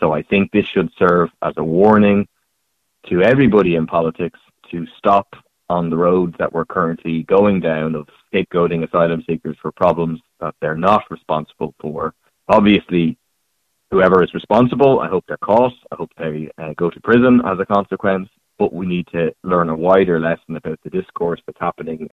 Deputy Murphy is warning all politicians to watch what they say about asylum seekers……………..